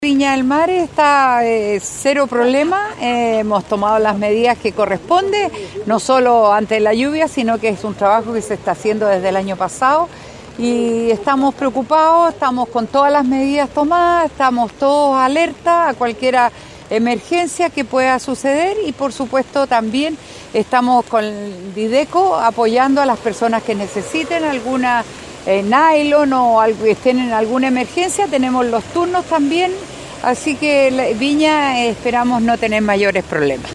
En ese sentido, la alcalde de la Ciudad Jardín, Virginia Reginato, realizó un positivo balance y señaló, que se encuentran en estado de alerta por este sistema frontal que irá aumentando su intensidad durante las próximas horas.